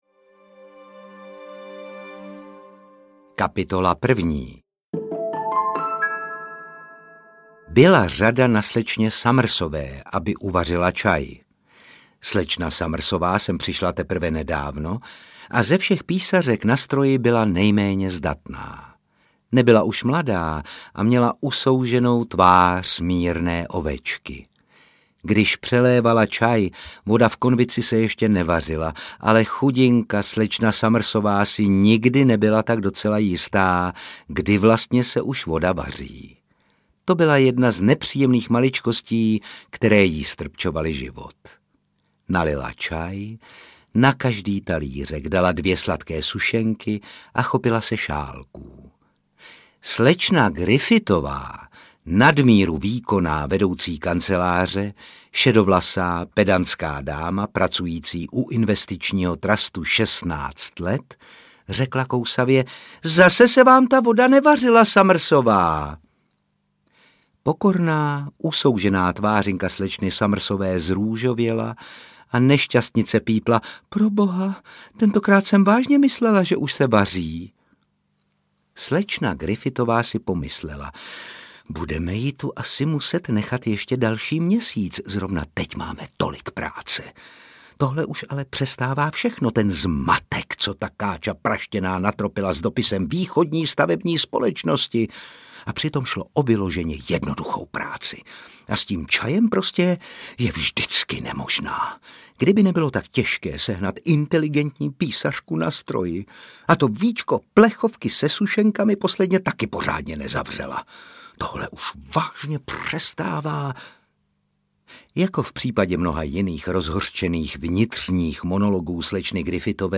Kapsa plná žita audiokniha
Ukázka z knihy
• InterpretRůžena Merunková, Otakar Brousek ml.